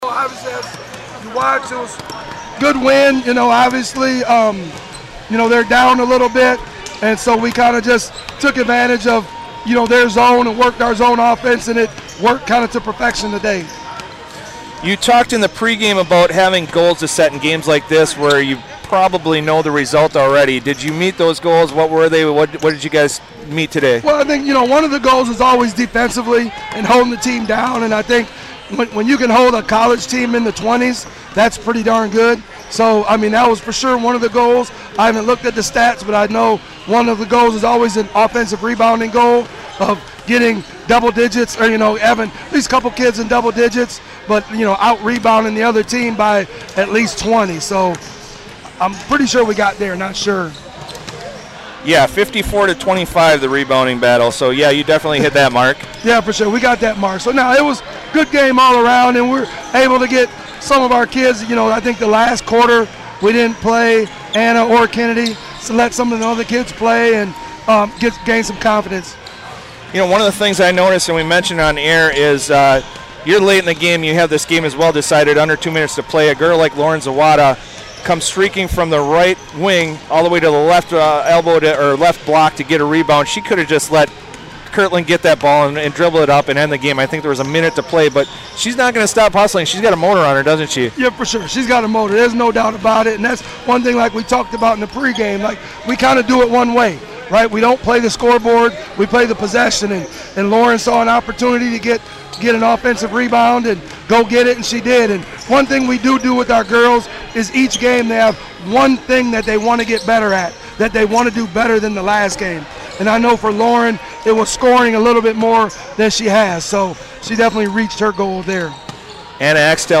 player spotlight interview